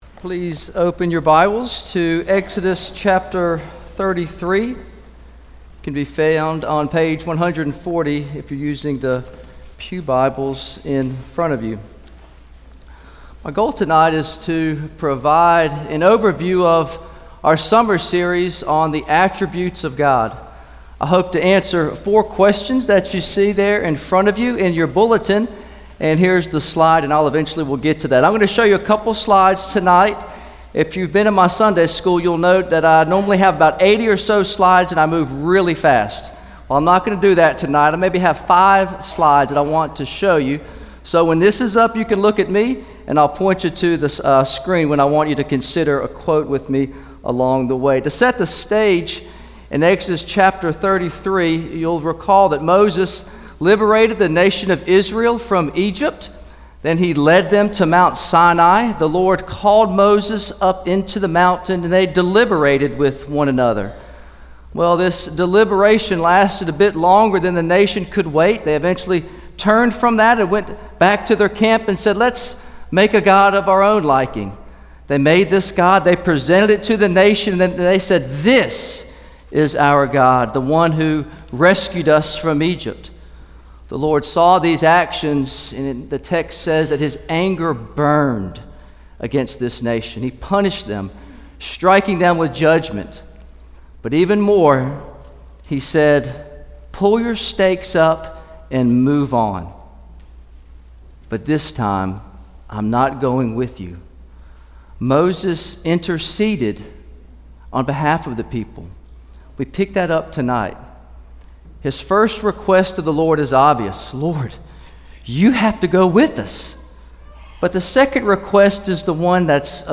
From Series: "Sunday Sermons"